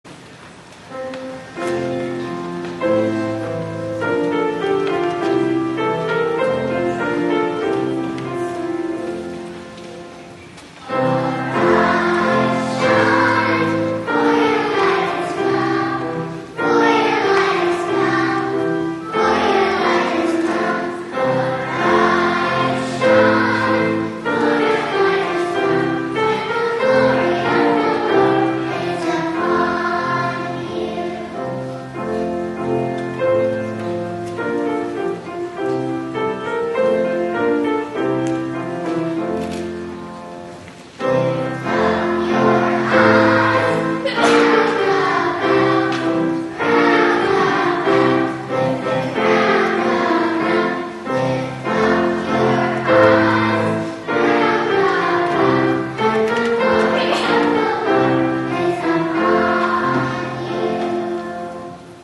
2008 media | Morningside Presbyterian Church
Arise, Shine / Knock, Knock, Knock / Little Drummer Boy (Children's Choir